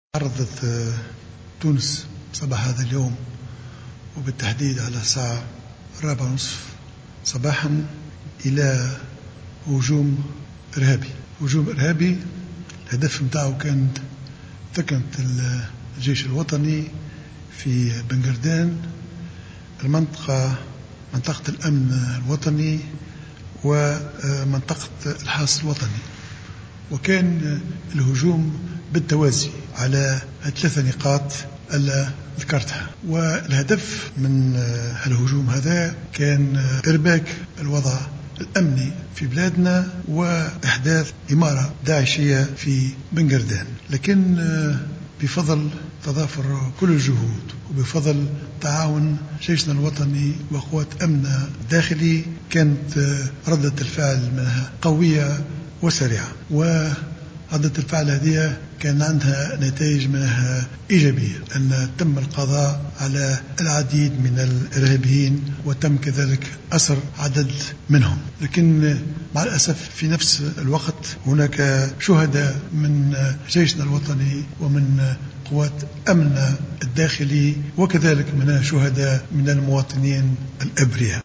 قال رئيس الحكومة الحبيب الصيد خلال كلمة ألقاها مساء اليوم إن الهجوم الإرهابي الذي جدّ في بن قردان كان الهدف منه إرباك الوضع الأمني وإحداث إمارة "داعشية" في الجهة.